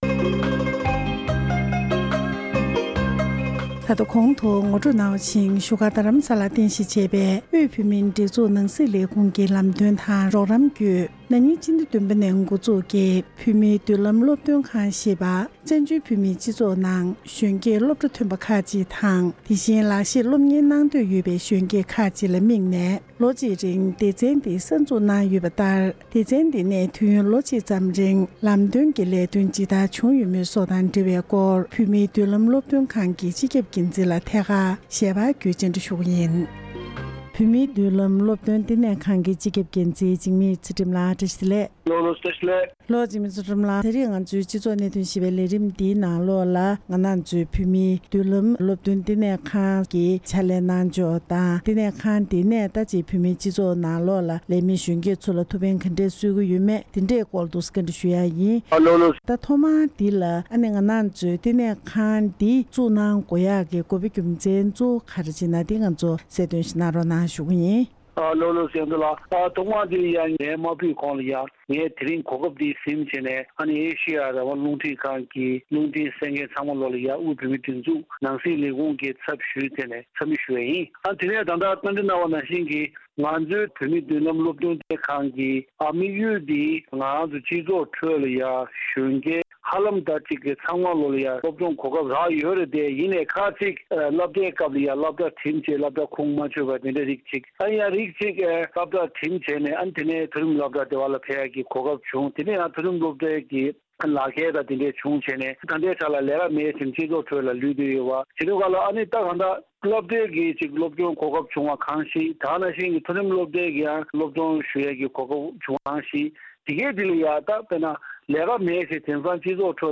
ཞལ་པར་བརྒྱུད་ཐད་ཀར་གནས་འདྲི་ཞུས་པ་ཞིག་གསན་རོགས་གནང་།།